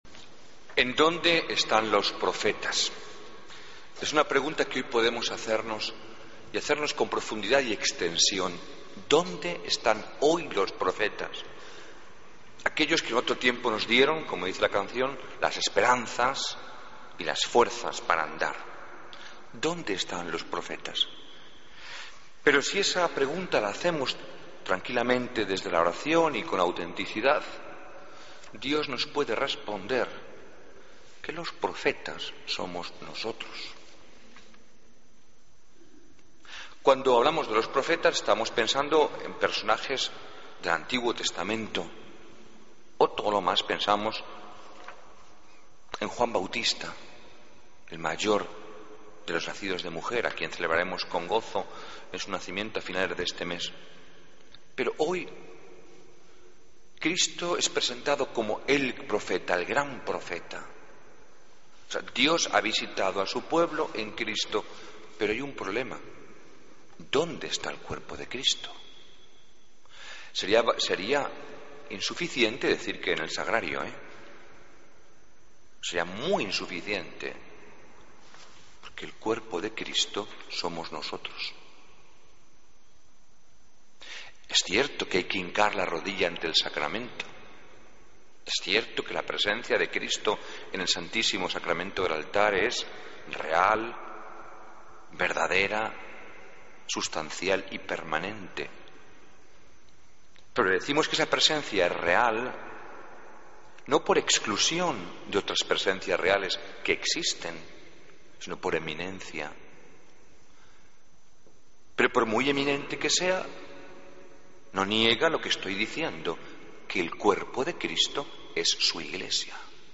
Homilía, domingo 9 de junio de 2013